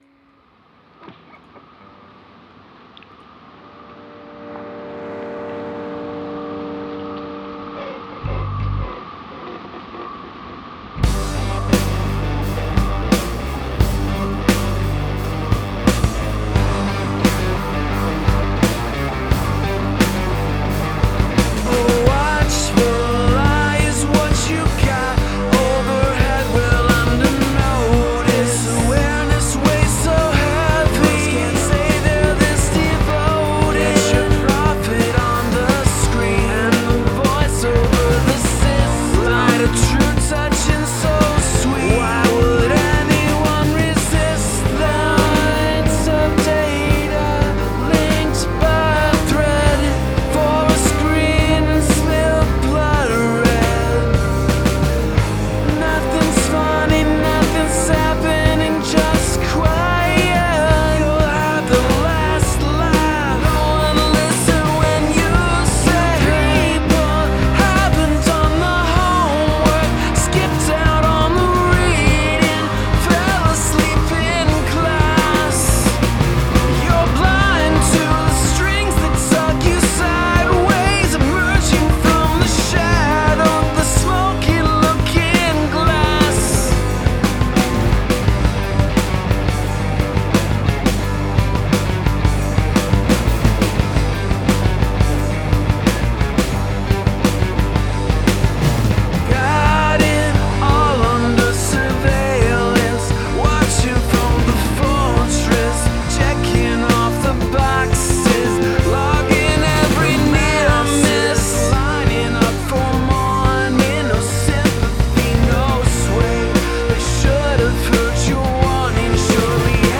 Use of field recording
Cool melody, I liked the twin melody lines.
That snare especially overwhelms my left ear.